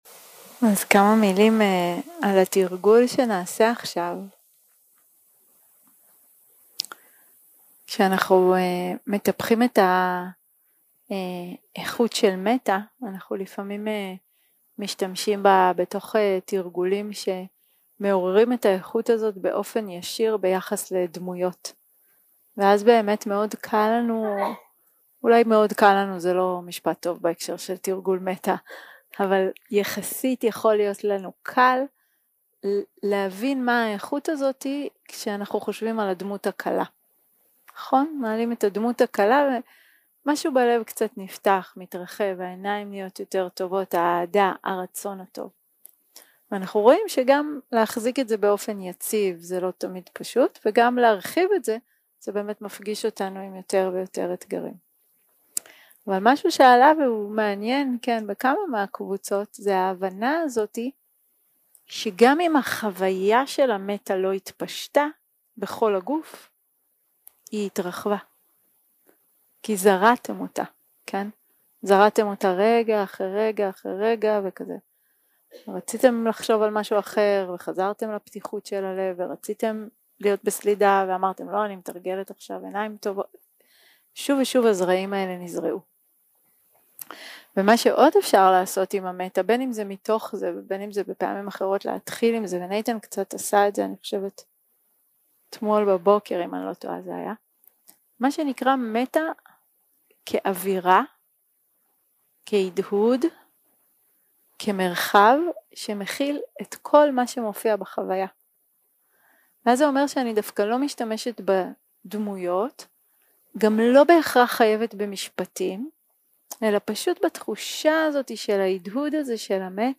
יום 6 – הקלטה 15 – צהריים – מדיטציה מונחית - מטא לכל התופעות 0:00 0:00 סוג ההקלטה: Dharma type: Guided meditation שפת ההקלטה: Dharma talk language: Hebrew